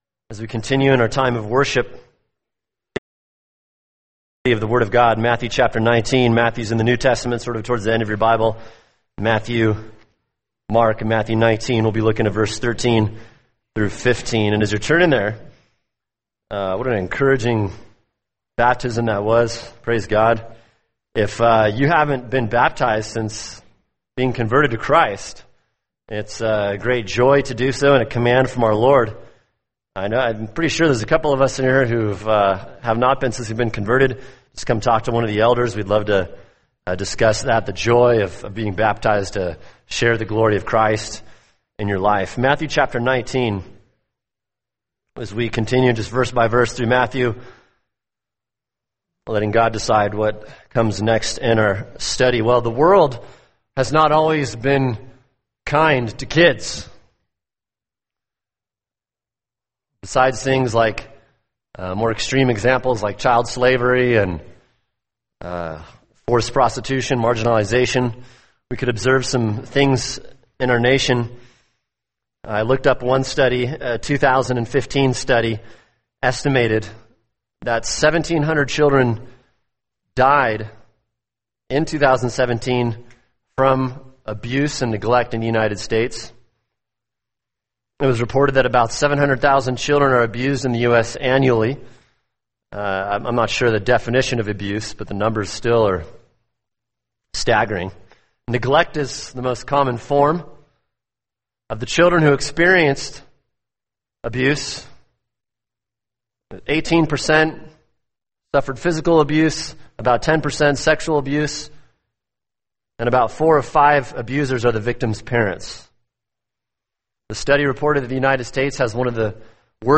[sermon] Matthew 19:13-15 Bringing Kids to Christ | Cornerstone Church - Jackson Hole